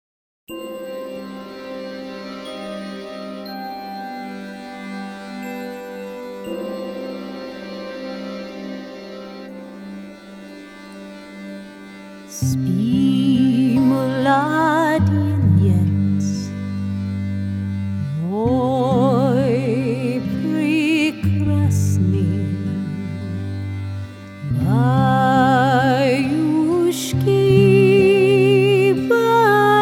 Russian lullaby